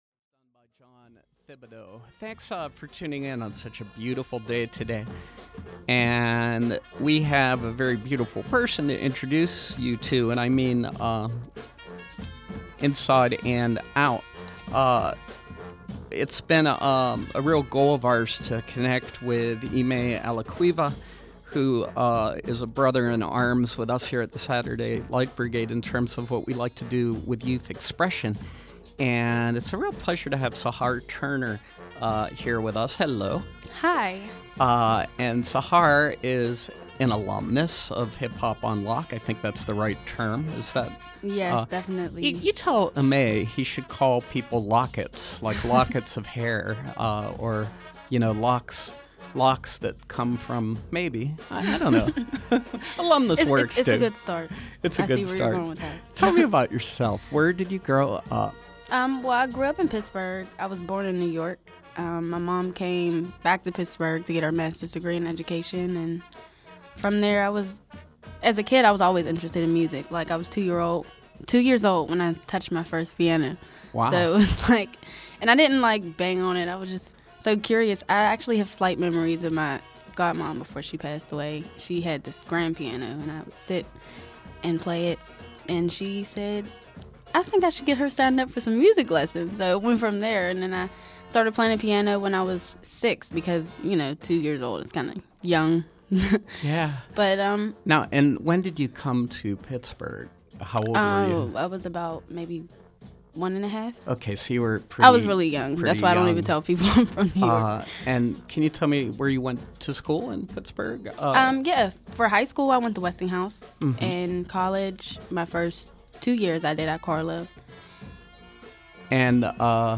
Vocalist
Performance , Youth Expression Showcase